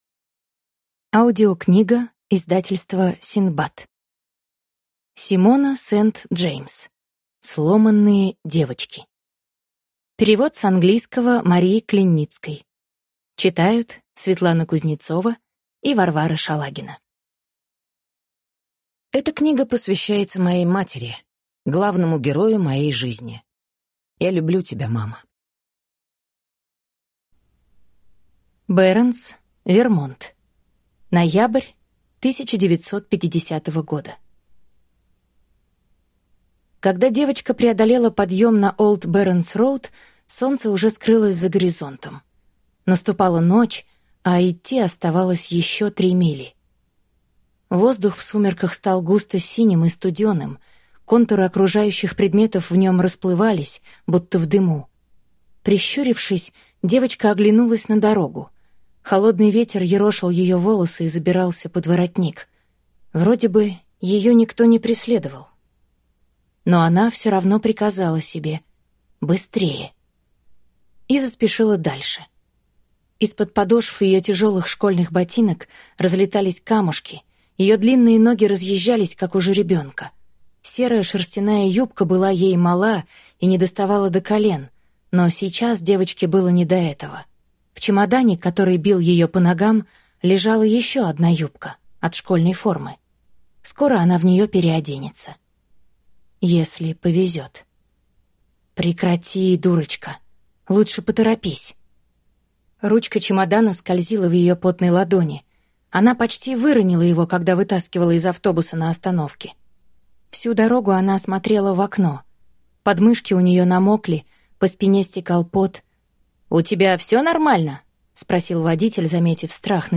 Аудиокнига Сломанные девочки | Библиотека аудиокниг
Прослушать и бесплатно скачать фрагмент аудиокниги